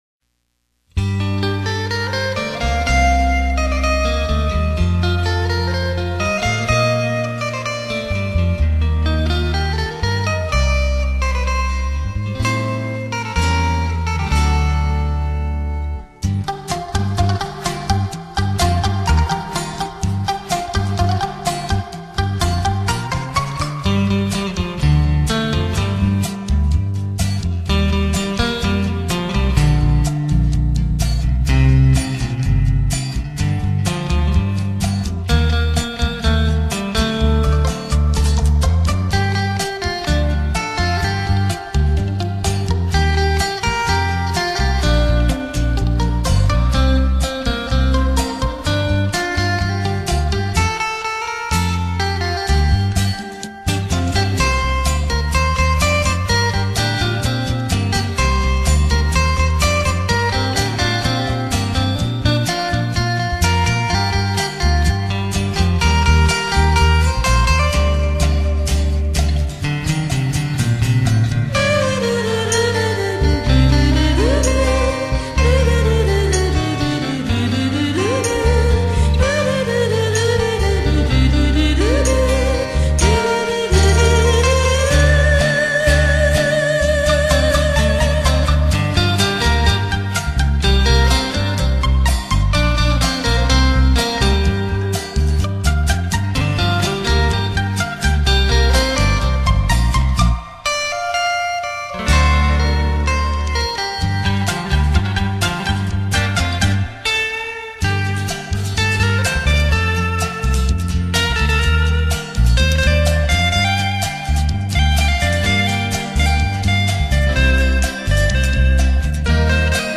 吉他的乐声，从不曾消失在你我的生命中